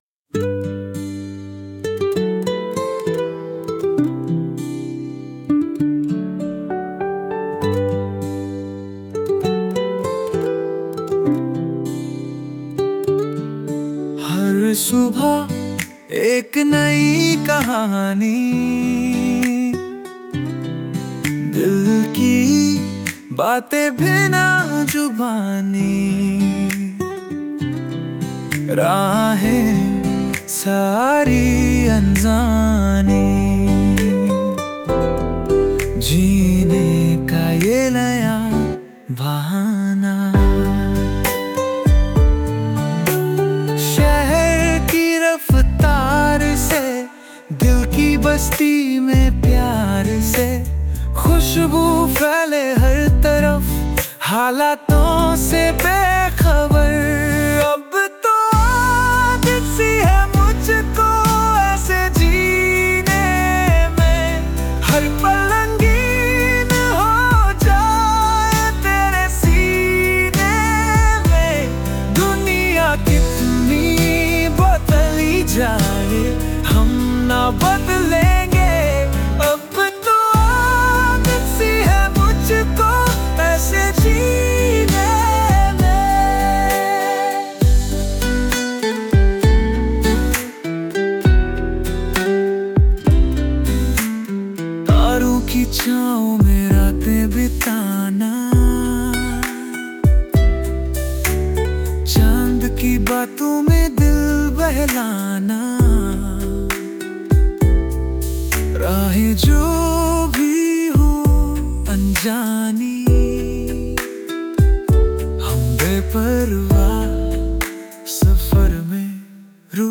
pop, fusion song.